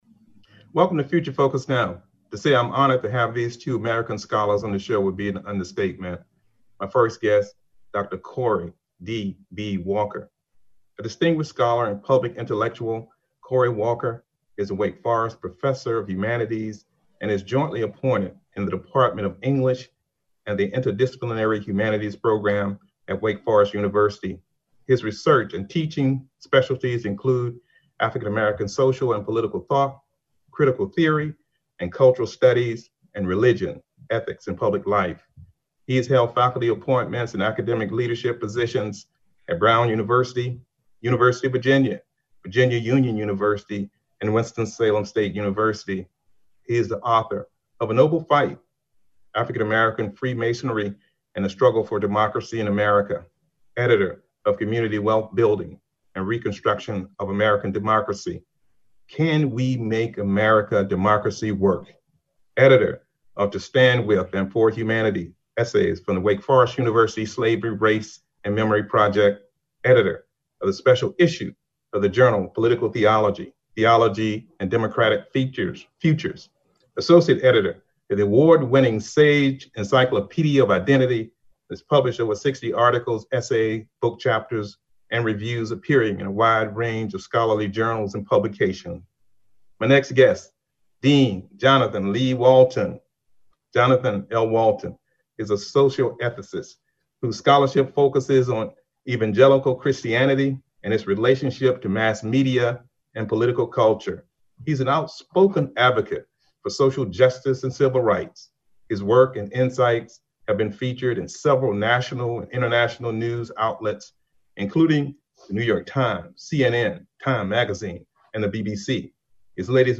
Future Focus is a one-hour public affairs talk show